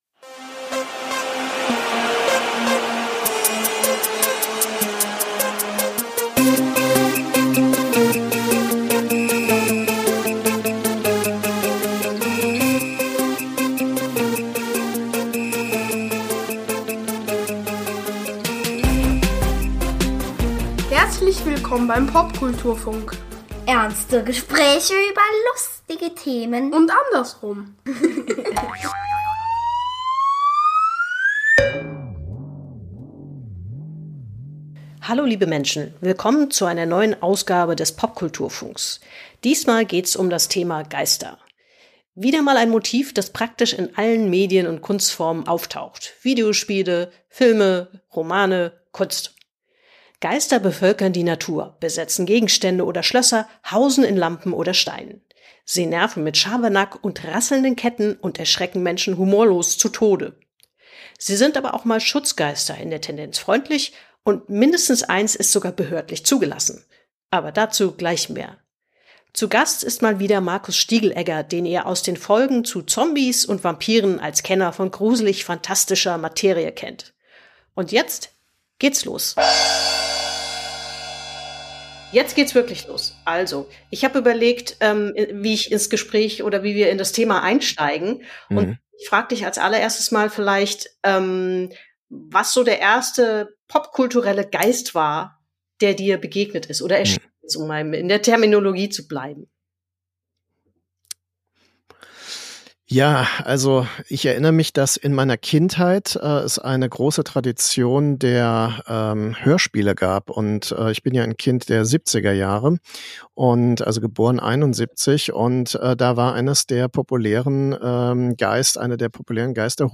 Ein Talk über Popkultur-Phänomene und Zeitgeisthemen. Von Arcades bis Zombies, und über alles dazwischen.
Und mit Gästen.